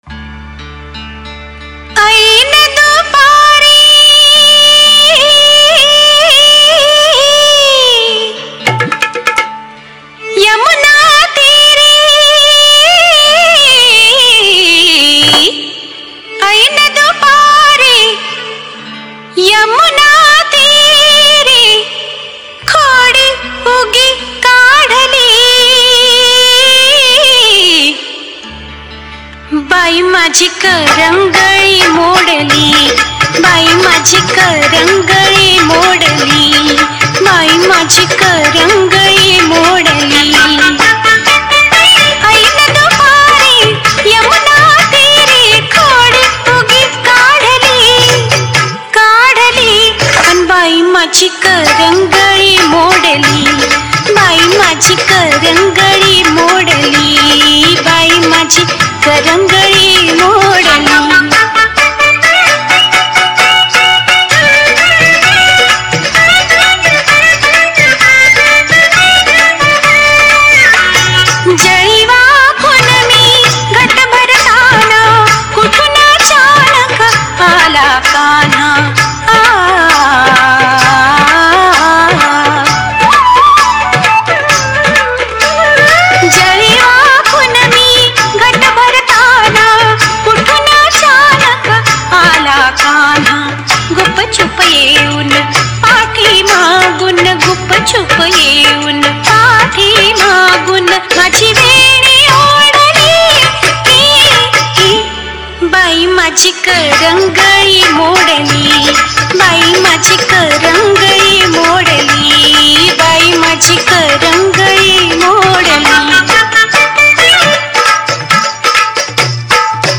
Marathi Dj Single 2025
Marathi Sound Check 2025